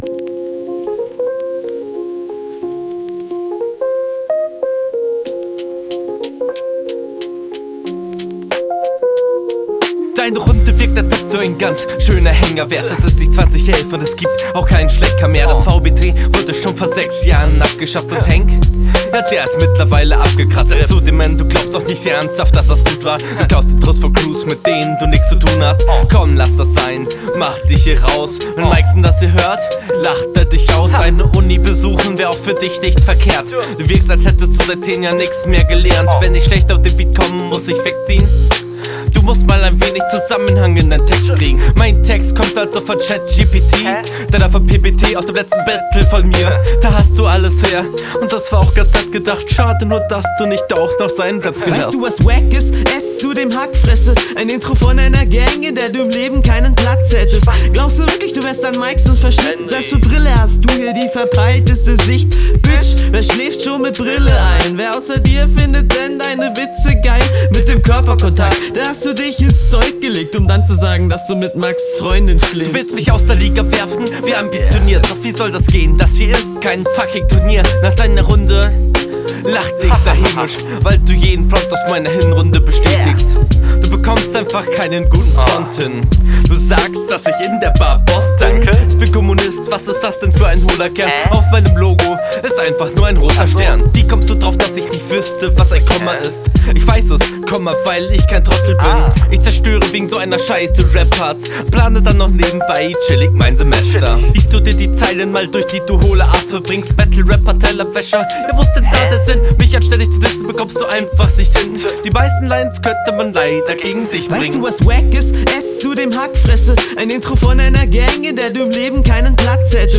Du kommst auf diesen Beat tatsächlich besser als auf deinem eigenen.
Stimmlich und flowlich okay.